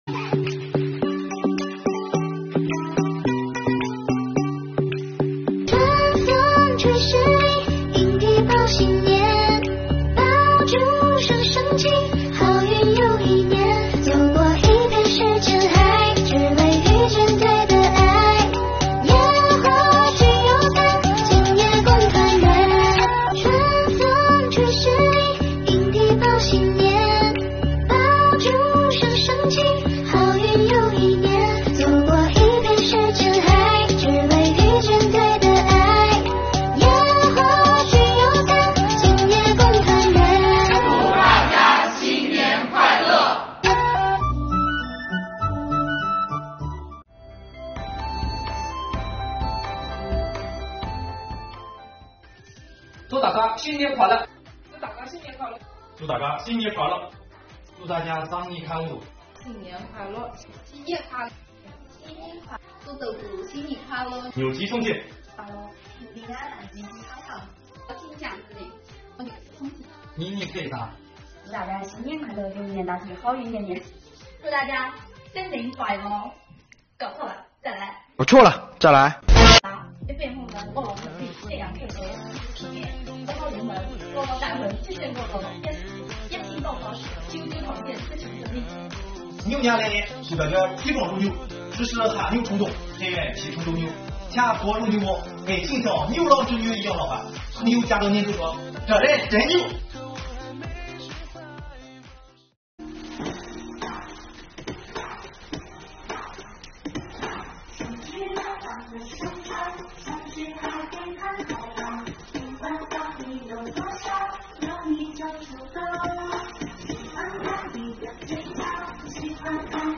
宝山税务青年们自导自演拜年视频
宝山区税务局五个团支部的青年们